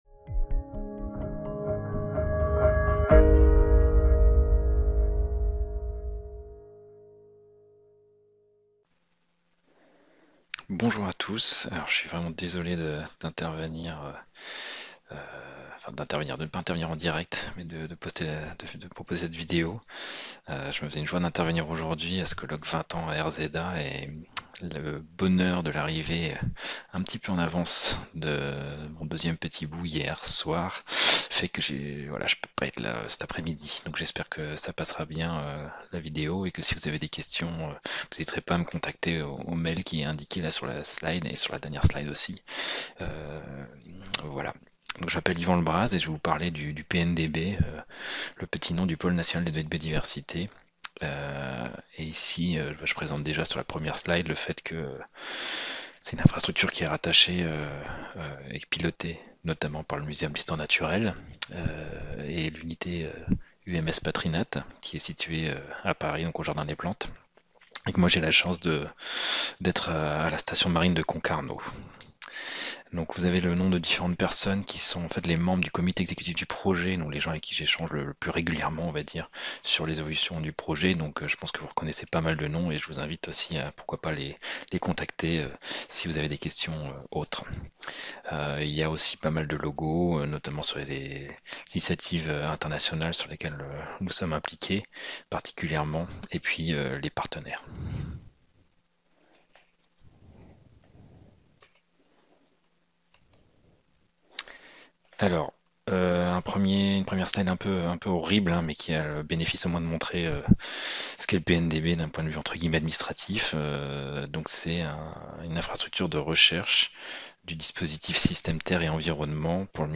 Conférence plénière - Vers un Pôle National de Données sur la Biodiversité | Canal U